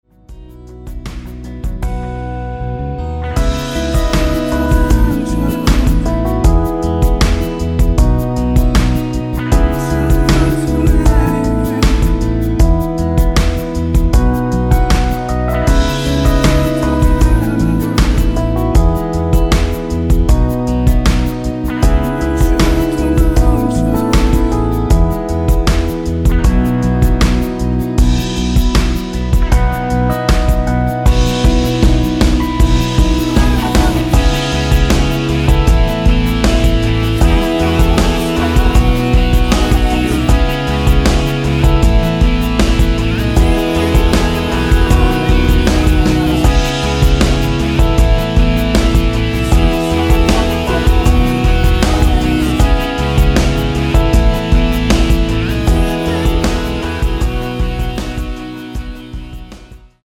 C#m
앞부분30초, 뒷부분30초씩 편집해서 올려 드리고 있습니다.